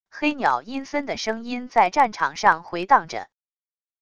黑鸟阴森的声音在战场上回荡着wav音频